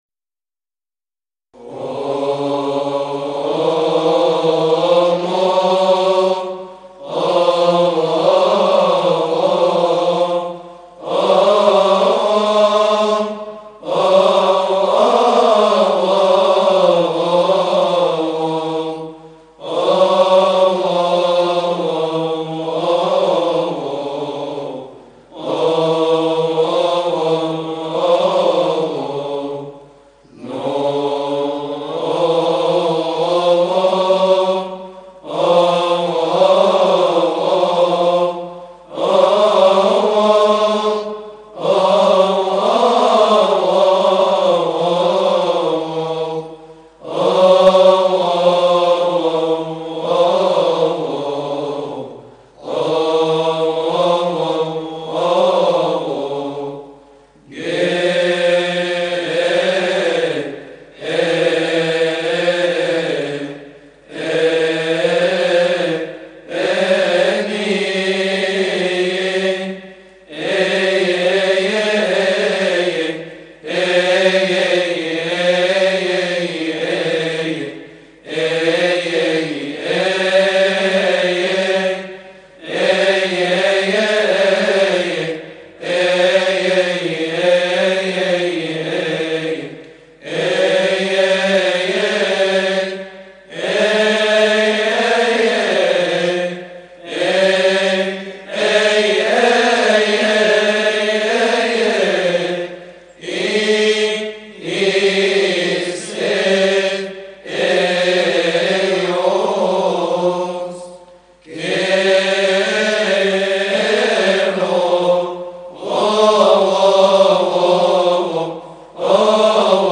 لحن_اومونوجنيس.mp3